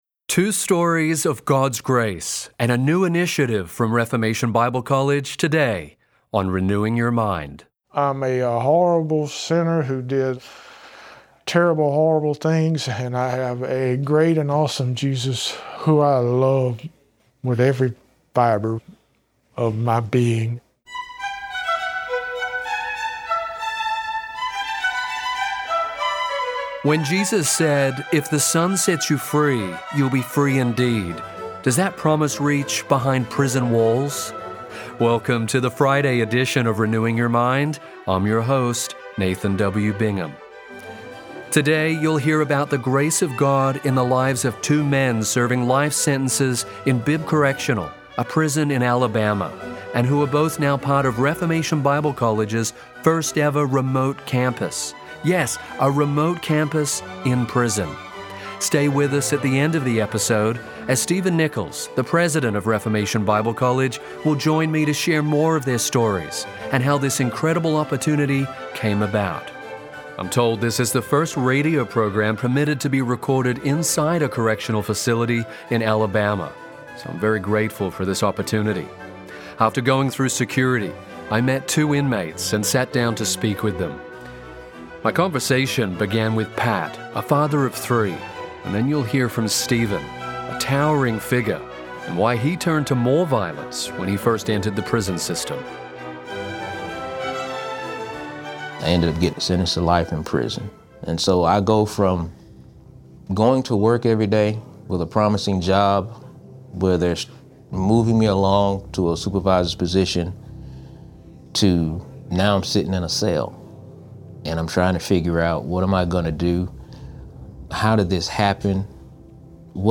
Within an Alabama prison, God is setting souls free. Today, meet two men who encountered the gospel while serving life sentences.